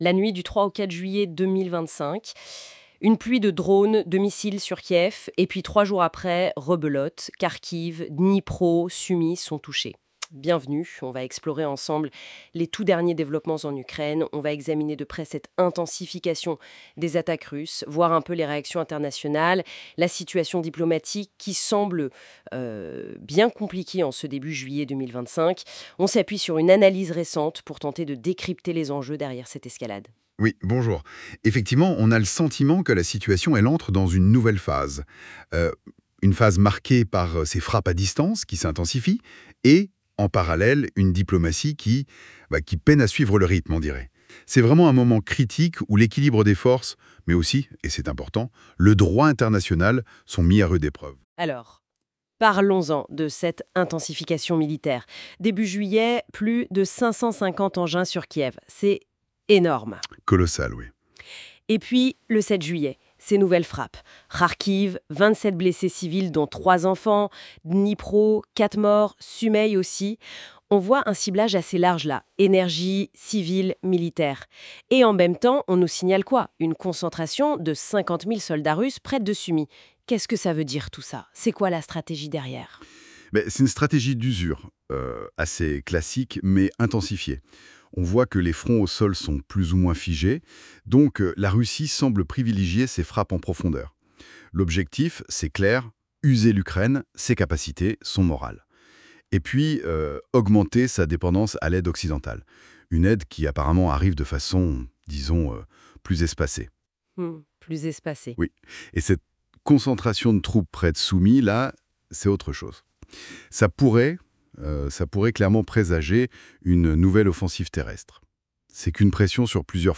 Vous pouvez écouter cet article en format débat-podcast